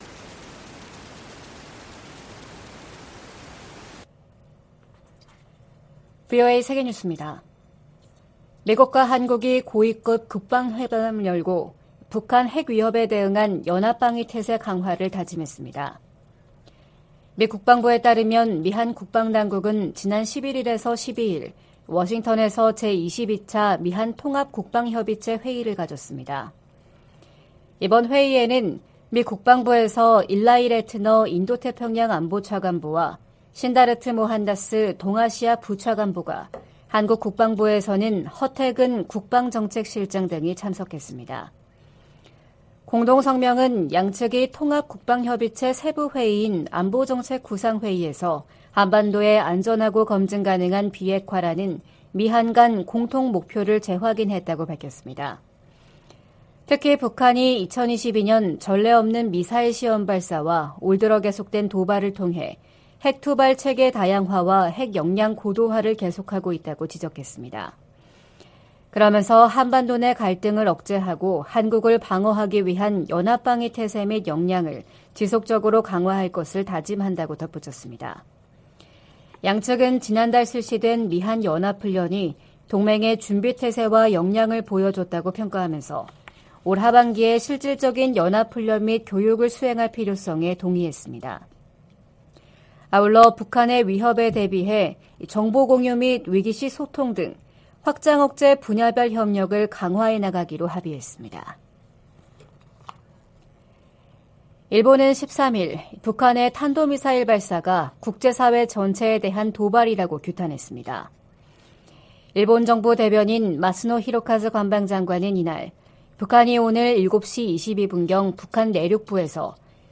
VOA 한국어 '출발 뉴스 쇼', 2023년 4월 14일 방송입니다. 북한이 중거리급 이상의 탄도 미사일을 동해쪽으로 발사했습니다.